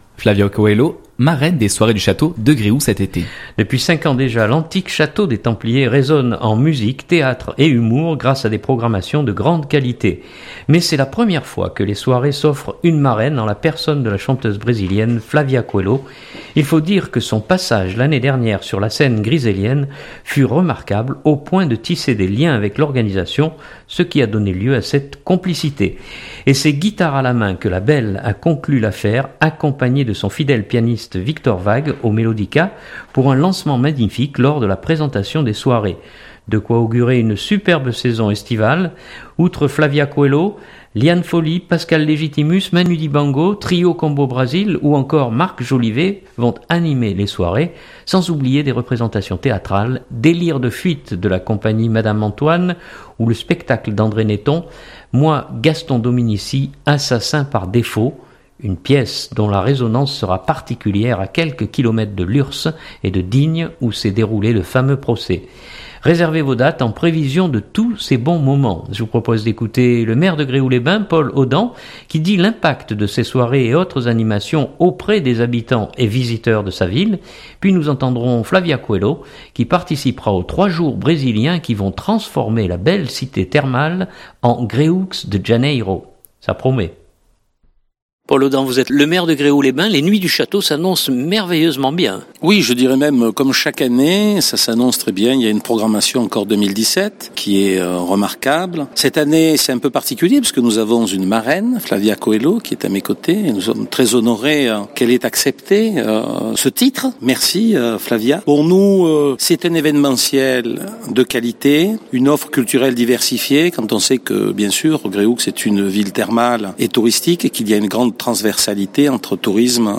Je vous propose d’écouter le Maire de Gréoux-les-Bains, Paul Audan qui dit l’impact de ces soirées et autres animations auprès des habitants et visiteurs de sa ville.